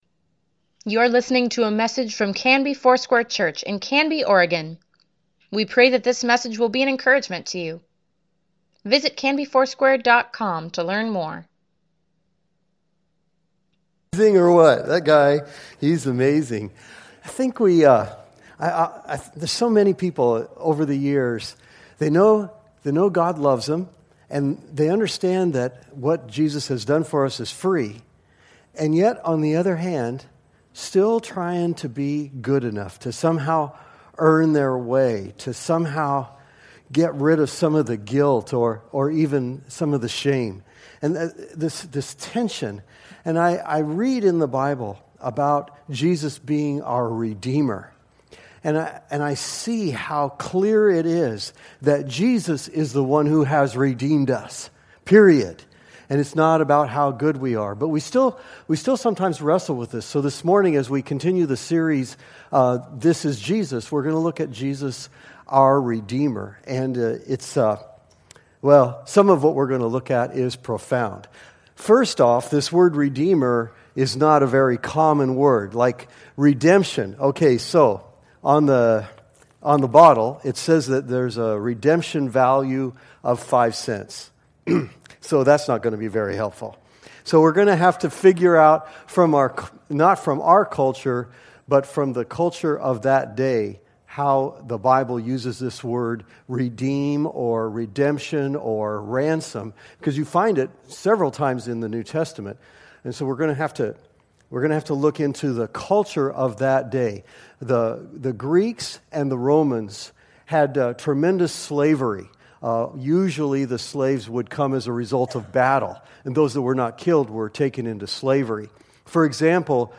Weekly Email Water Baptism Prayer Events Sermons Give Care for Carus This is Jesus: The People He Redeems November 19, 2017 Who does Jesus redeem?